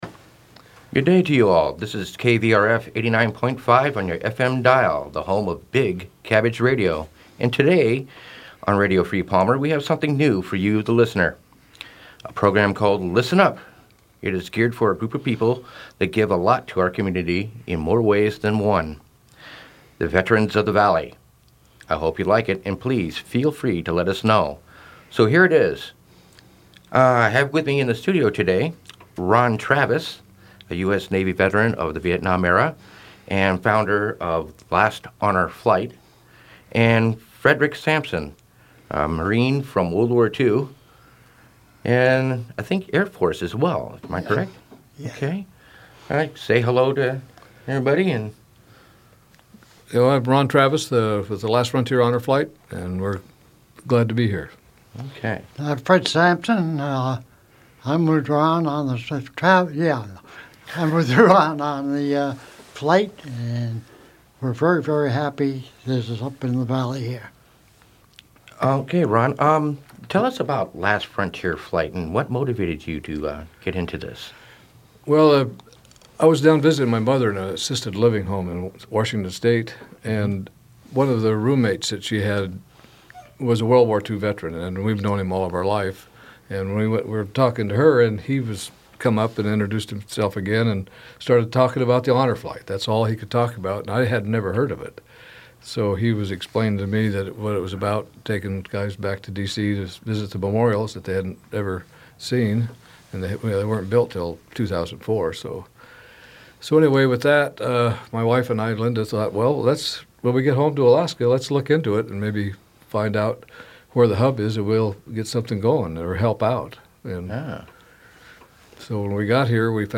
Listen up! Interviews of Honor flight Veterans